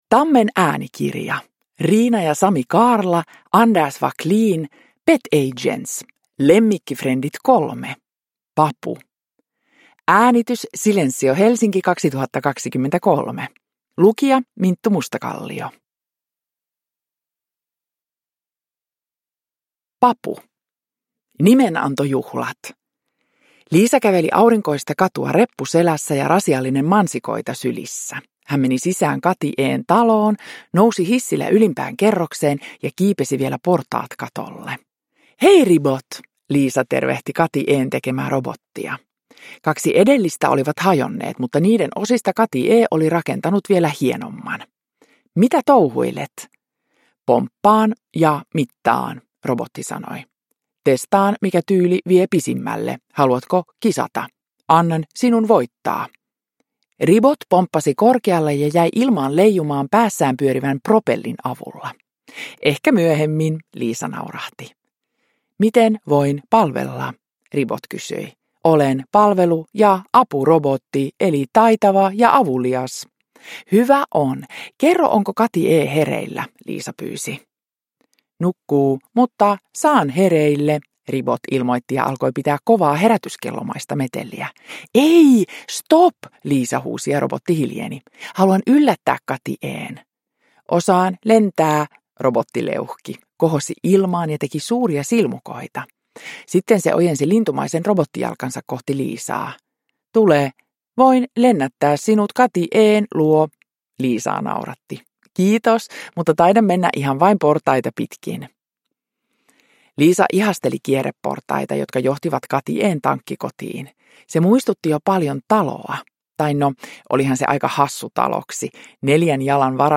Produkttyp: Digitala böcker
Uppläsare: Minttu Mustakallio